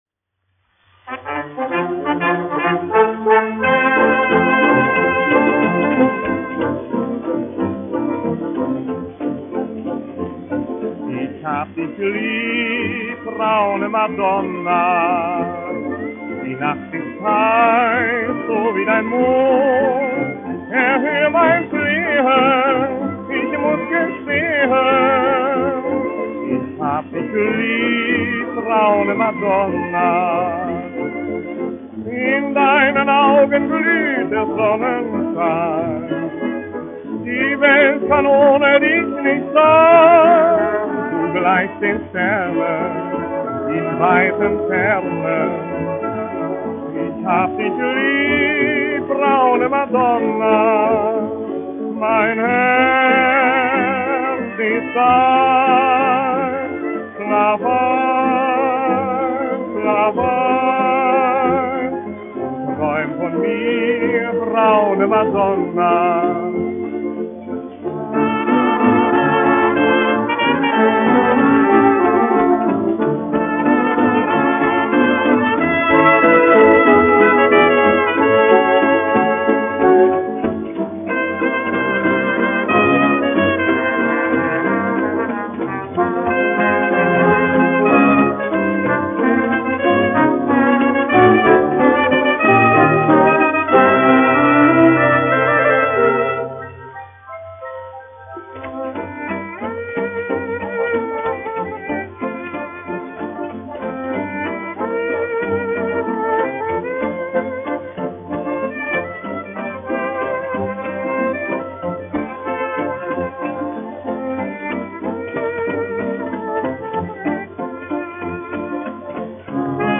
1 skpl. : analogs, 78 apgr/min, mono ; 25 cm
Sarīkojumu dejas
Populārā mūzika
Skaņuplate